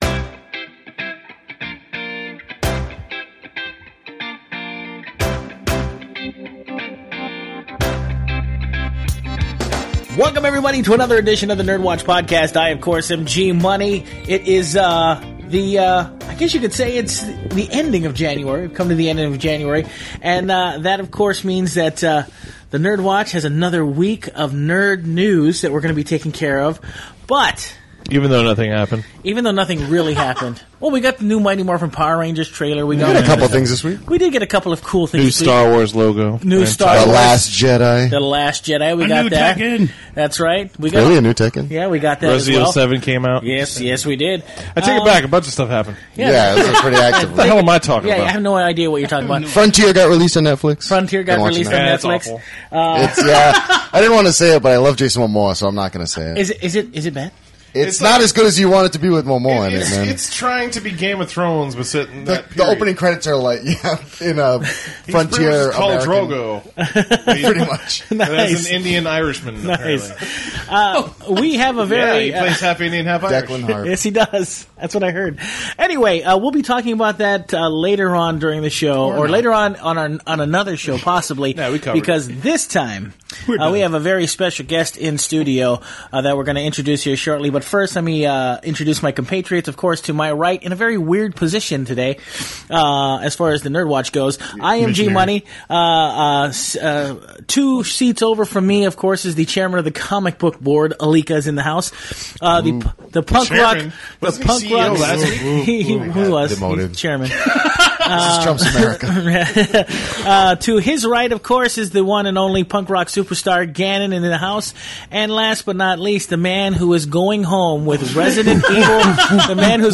NERDWatch Interviews